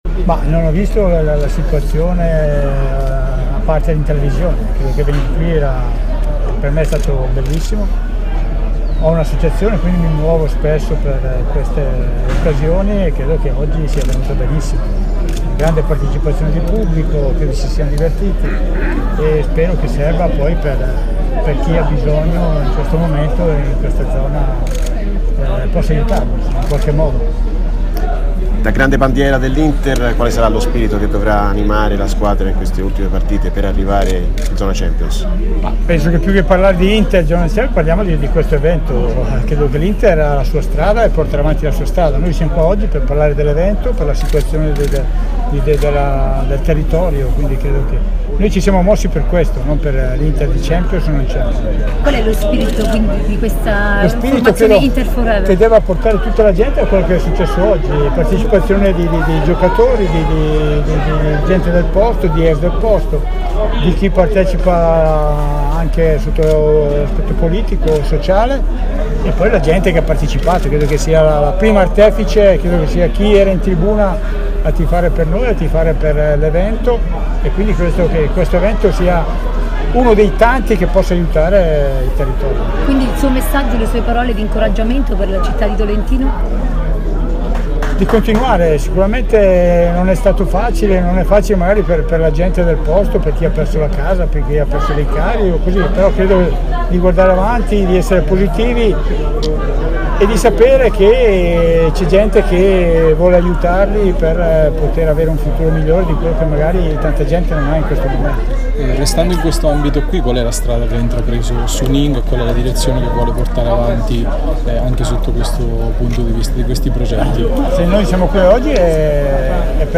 Beppe Baresi, ex giocatore, intervistato
a margine dell'appuntamento a Tolentino di Inter Forever